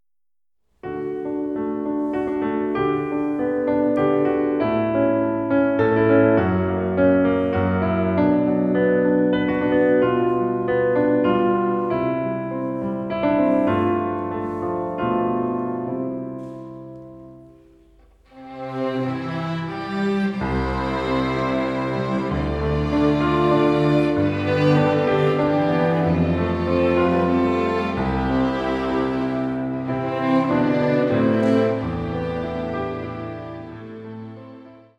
Streichensemble